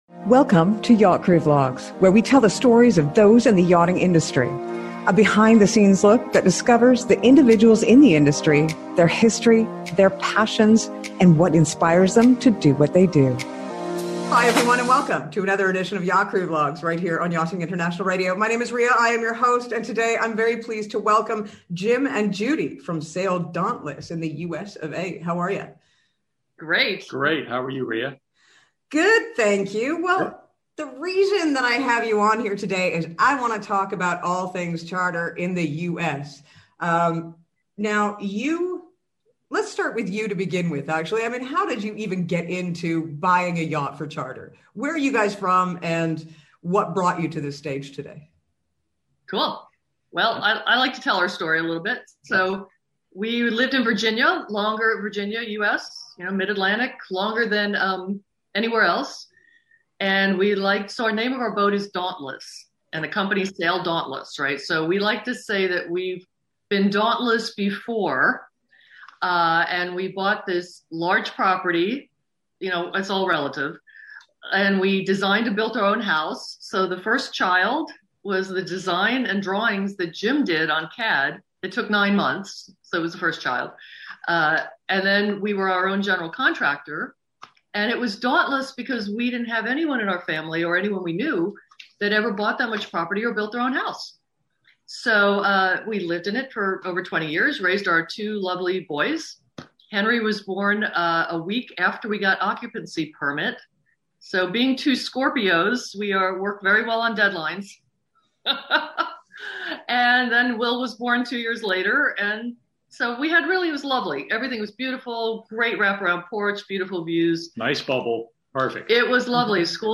One of the most funnest interviews that we have had the pleasure of doing in while!